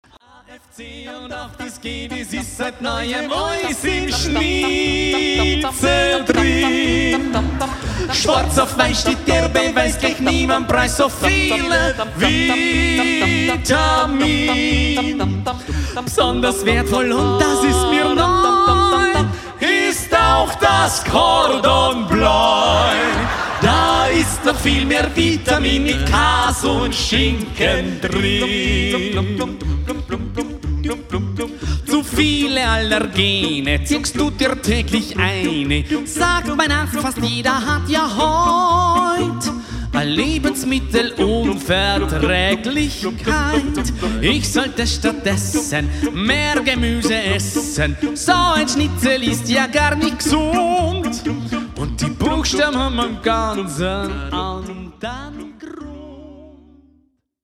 die LIVE-CD zum gleichnamigen Programm